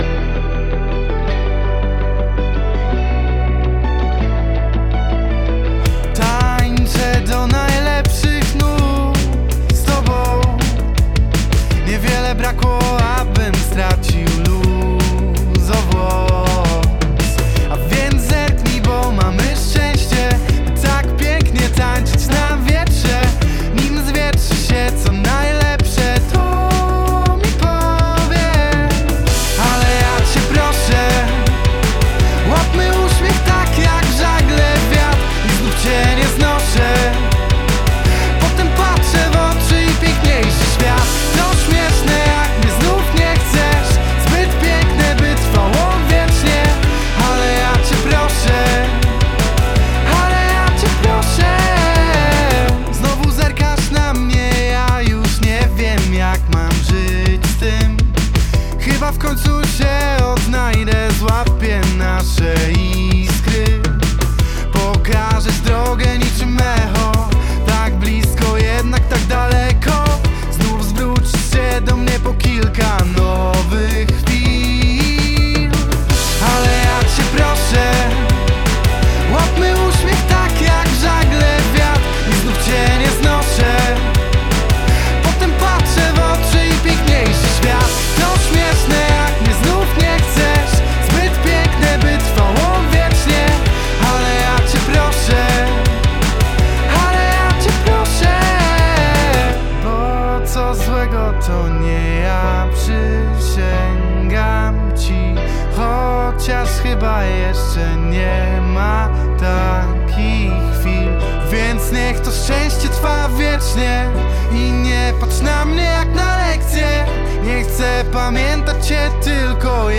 rytmicznie wpada w ucho!
Charakterystyczny wokal artysty w mocnym, popowym kawałku.